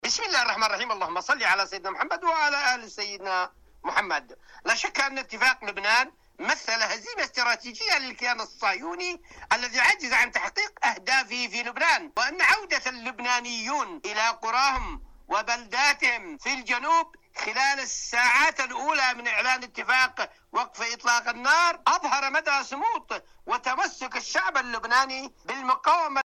البث المباشر